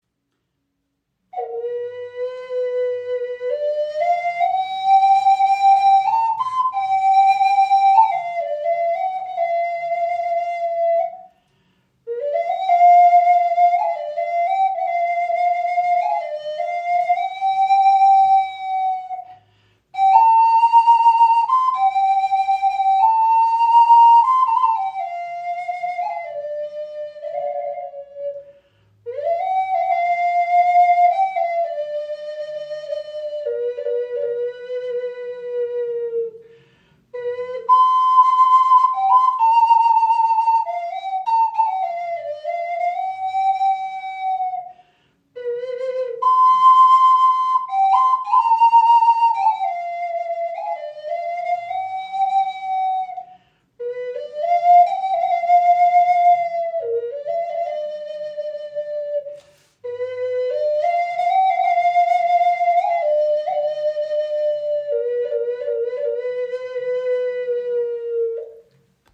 Okarina aus einem Aststück | C5 in 432 Hz | Pentatonische Stimmung | ca. 18 cm
Handgefertigte 5 Loch Okarina aus Teakholz – pentatonische C5 Moll Stimmung in 432 Hz, warmer klarer Klang, jedes Stück ein Unikat.
In pentatonischer C5 Moll Stimmung auf 432 Hz gestimmt, entfaltet sie einen warmen, klaren Klang, der Herz und Seele berührt.
Trotz ihrer handlichen Grösse erzeugt sie einen angenehm tiefen und warmen Klang – fast ebenbürtig zur nordamerikanischen Gebetsflöte.